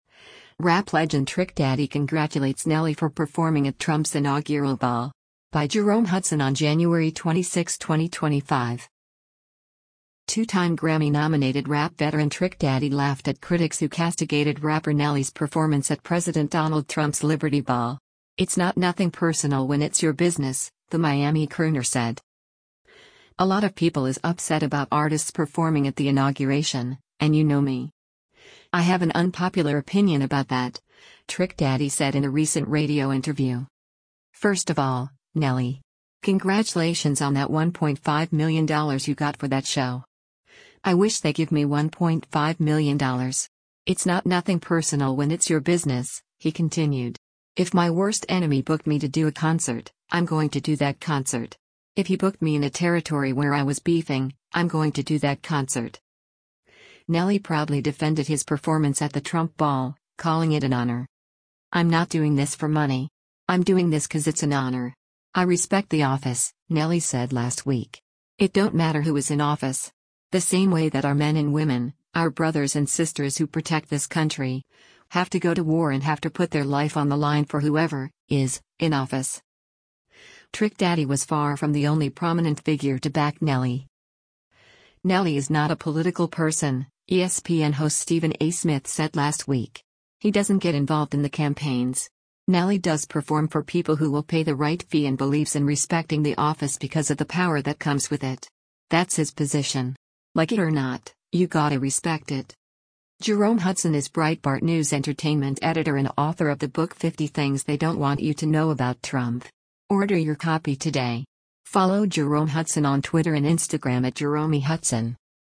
“A lot of people is upset about artists performing at the inauguration—and you know me. I have an unpopular opinion about that,” Trick Daddy said in a recent radio interview.